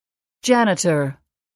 단어번호.0648 대단원 : 3 소단원 : a Chapter : 03a 직업과 사회(Work and Society)-Professions(직업) janitor [dƷǽnətər] 명) 문지기, 수위, 관리인 mp3 파일 다운로드 (플레이어바 오른쪽 아이콘( ) 클릭하세요.)